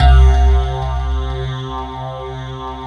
FLNGHARPC2-L.wav